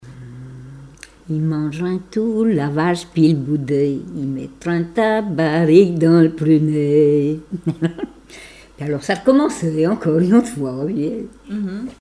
chanter le lendemain d'une noce
Chavagnes-les-Redoux
Genre brève
Pièce musicale inédite